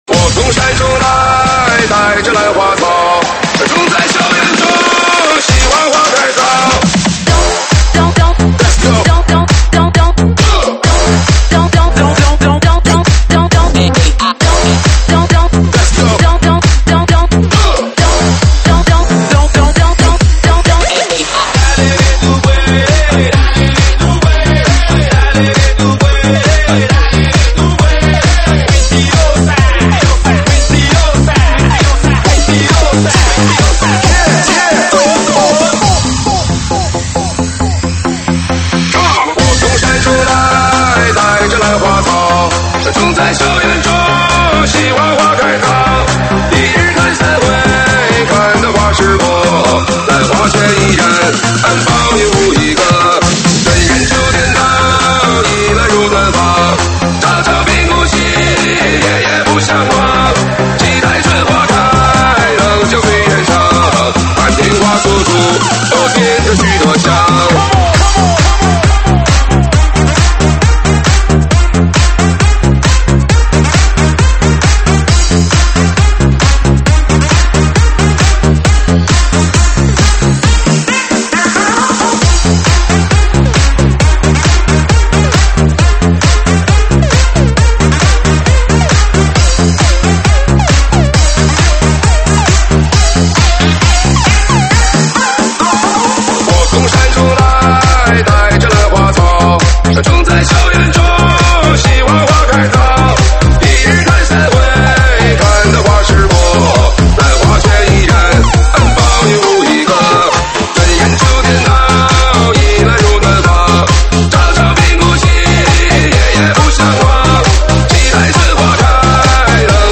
舞曲类别：车载大碟